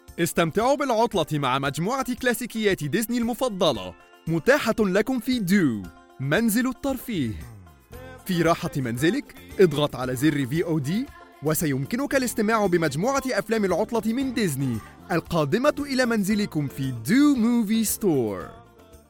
Arabic (Egypt), Middle Eastern, Male, 20s-30s